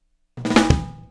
Retro Funkish Beat Intro 02.wav